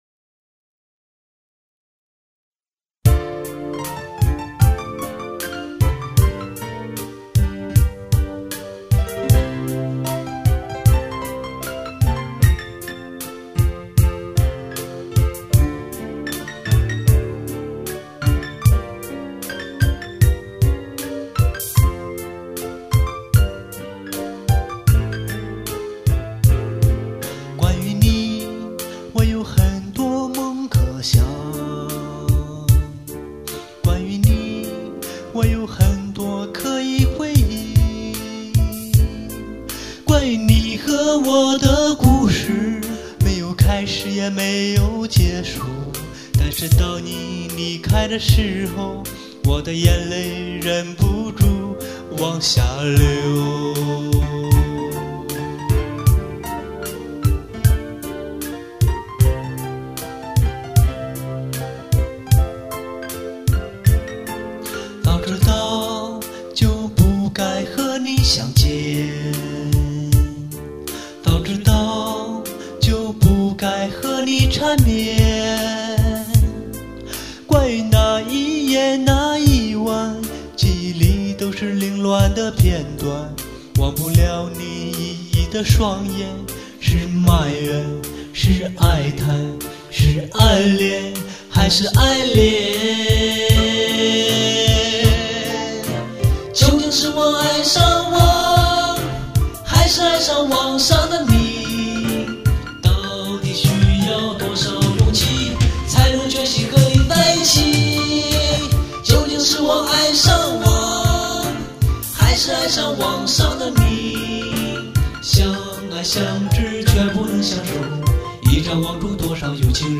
男生版